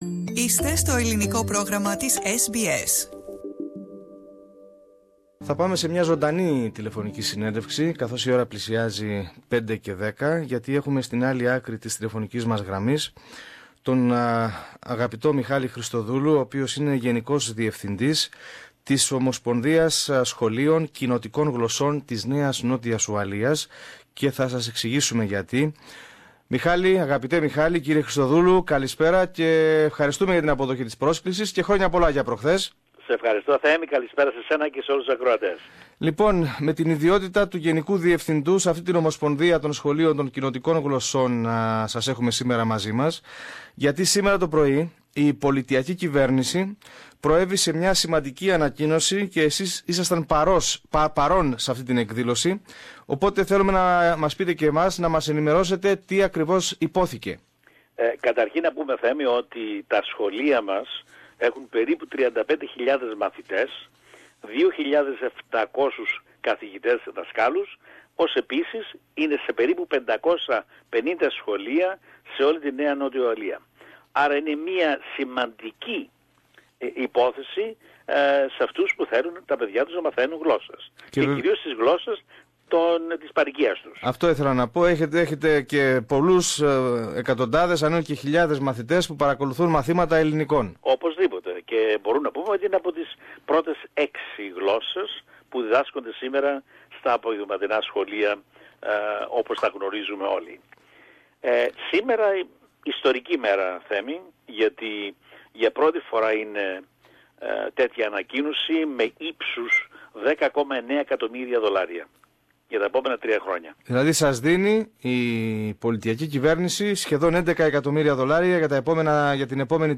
Επιπρόσθετη οικονομική ενίσχυση για τα σχολεία κοινοτικών γλωσσών ανακοίνωσε η κυβέρνηση της ΝΝΟ. Περισσότερα πληροφορούμαστε στην συνομιλία